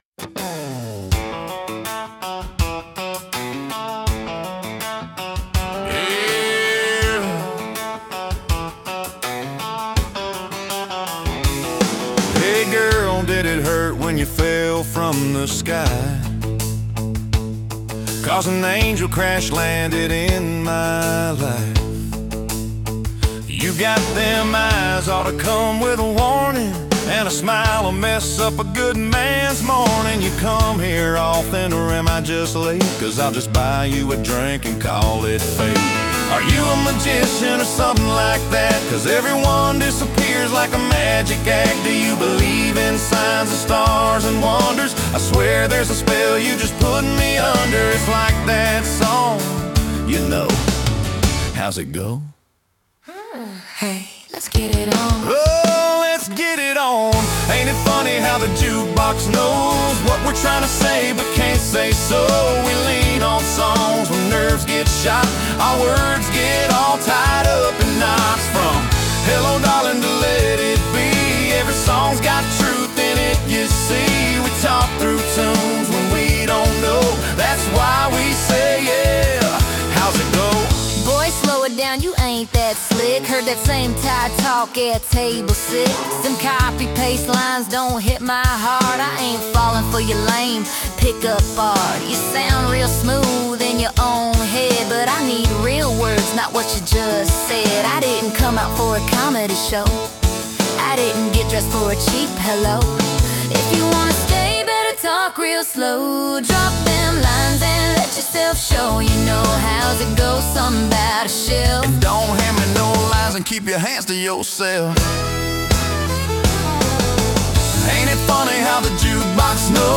Country
acoustic guitar, bass, volcals